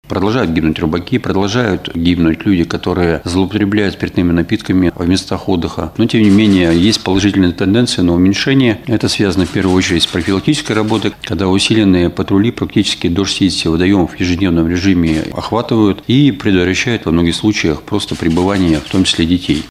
В прошлом году несчастных случаев было больше — 27, — рассказал заместитель руководителя МЧС Свердловской области Алексей Морозов на пресс-конференции «ТАСС-Урал».